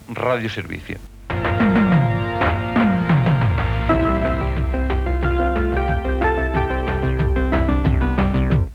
Identificació de l'emissora
FM